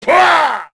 Jin-Vox_Attack5_kr.wav